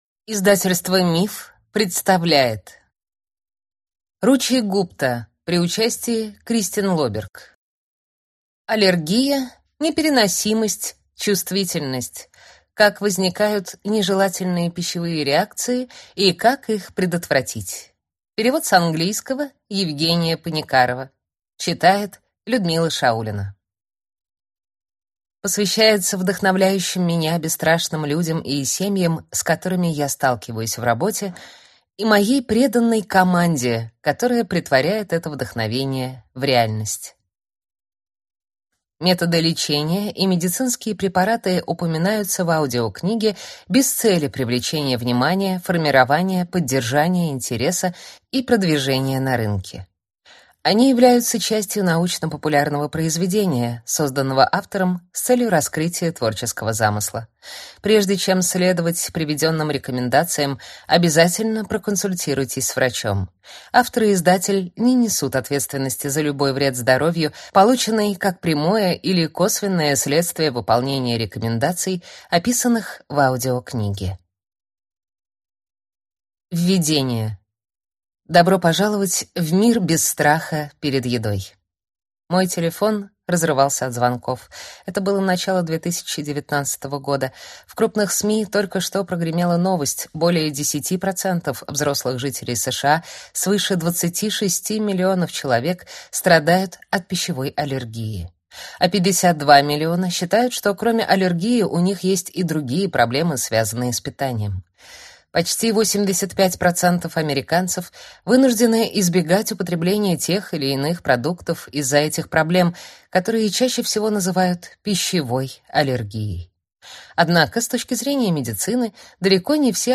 Аудиокнига Аллергия, непереносимость, чувствительность. Как возникают нежелательные пищевые реакции и как их предотвратить | Библиотека аудиокниг